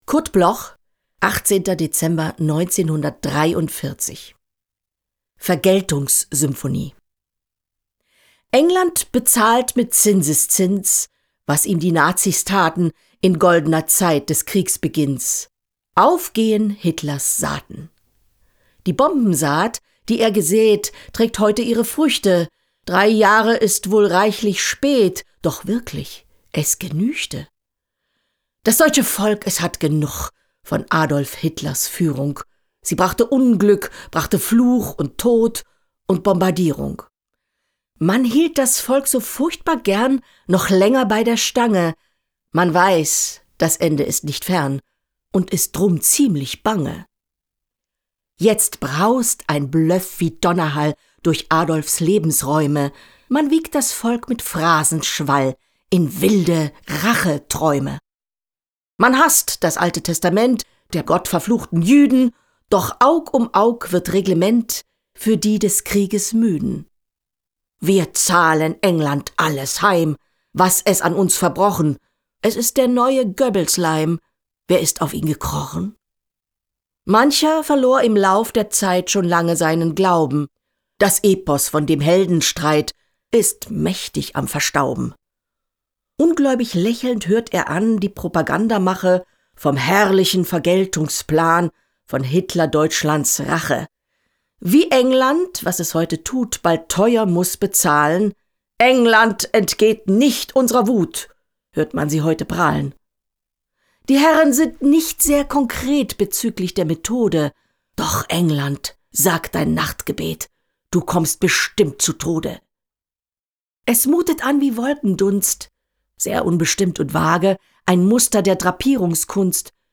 Recording: Killer Wave Studio, Hamburg · Editing: Kristen & Schmidt, Wiesbaden